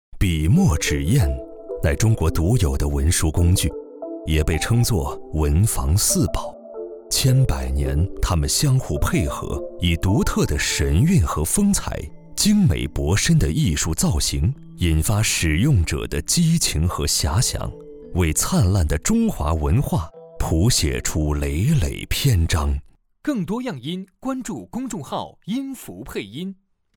纪录片-男2-自然讲述-纪录片.mp3